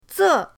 ze4.mp3